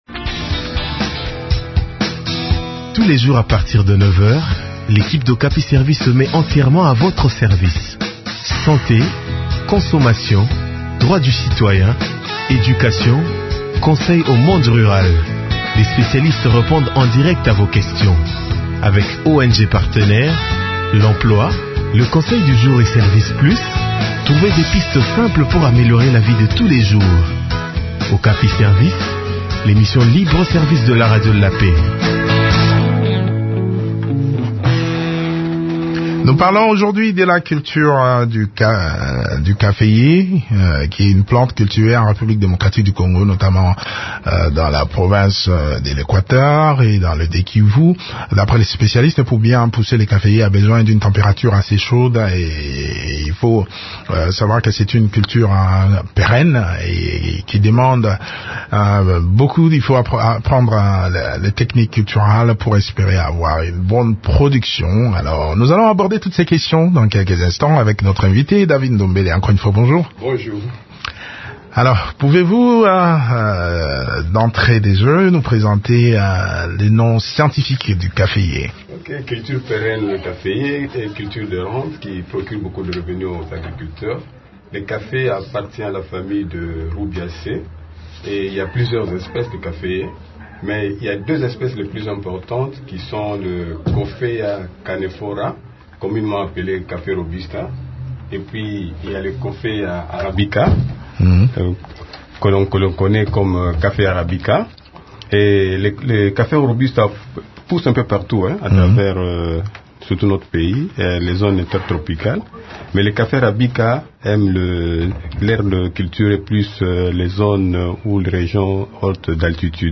s’entretient sur ce thème avec l’ingénieur agronome